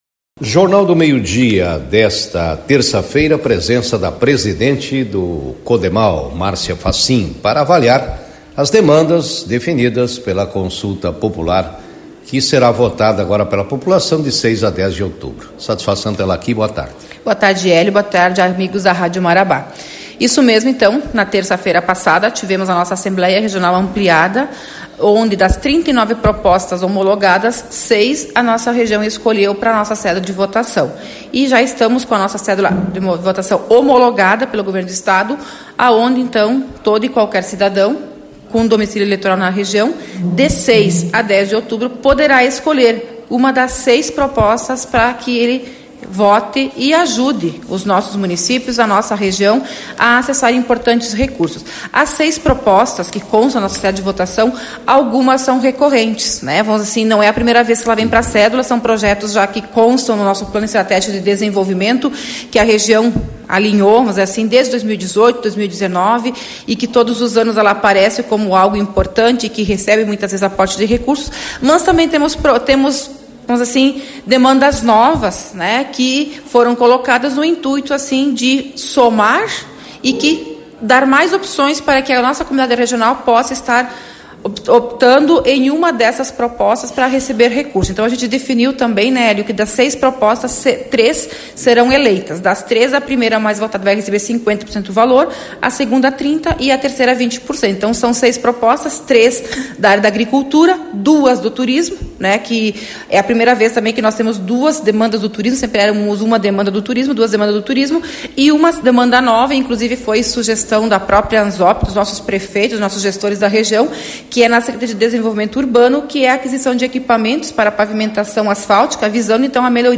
O repórter